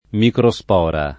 [ mikro S p 0 ra ]